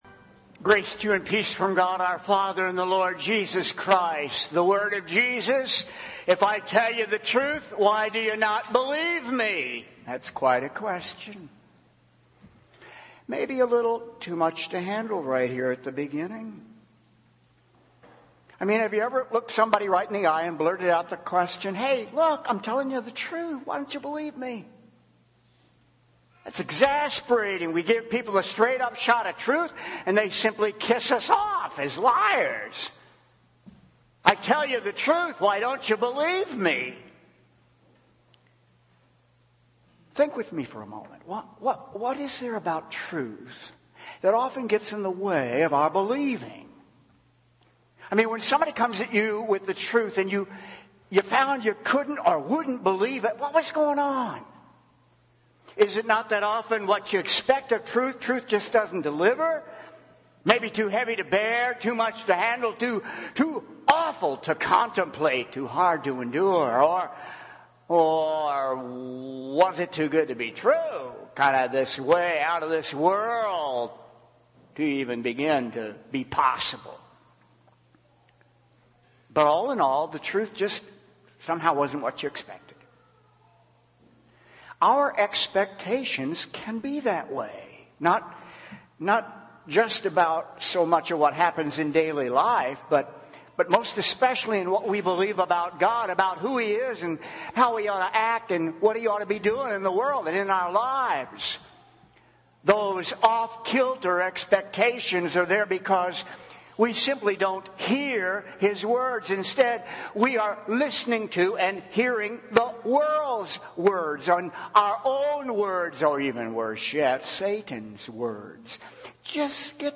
Home › Sermons › Reformation Sunday